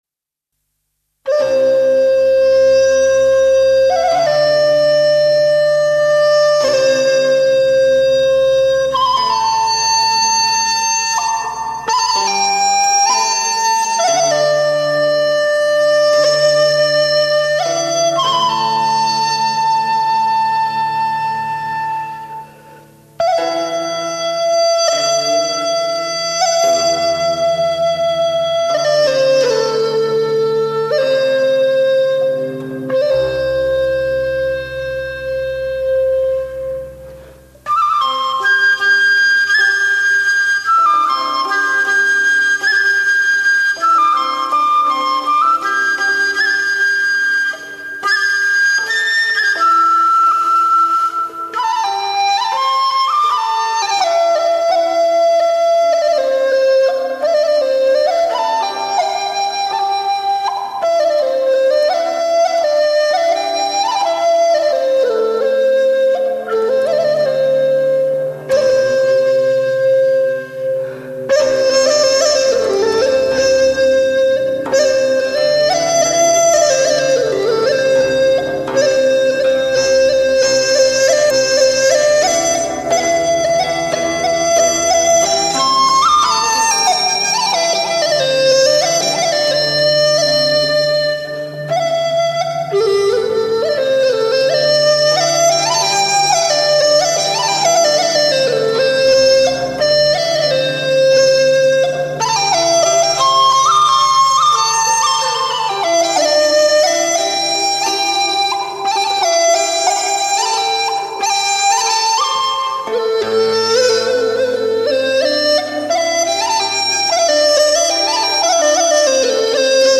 竹笛，雅号横吹，发音动人，婉转，
把您带入鸟语花香或高山流水的意境之中。